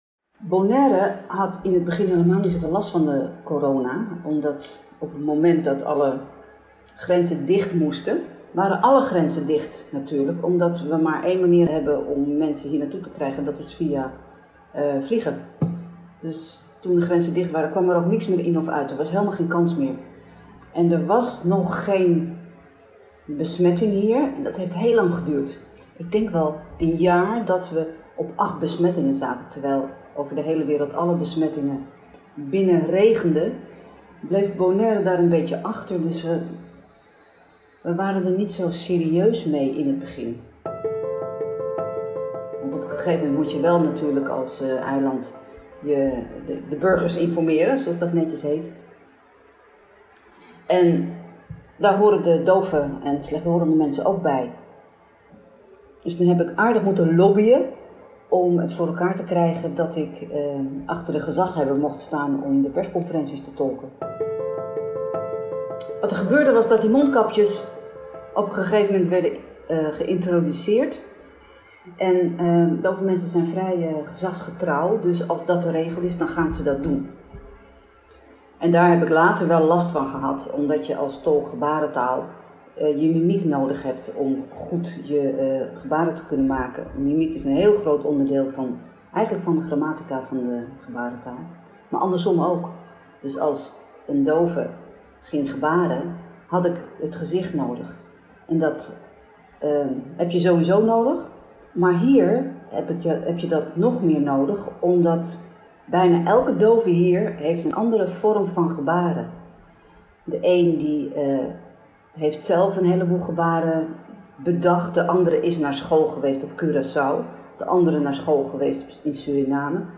Verteller